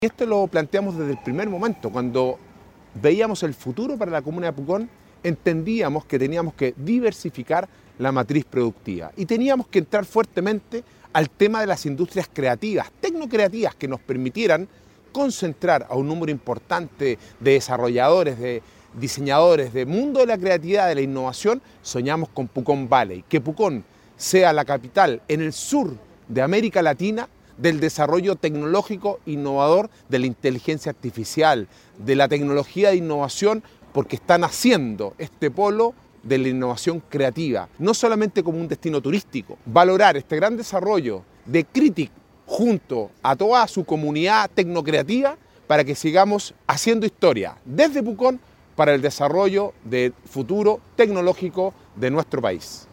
Así lo recalcó el alcalde de la comuna, Sebastián Álvarez.